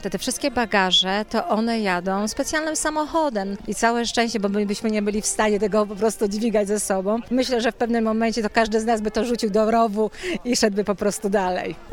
Przez to walizki lub plecaki mogą być ciężkie, dlatego jedna z uczestniczek pielgrzymki opowiedziała, co robią z bagażami.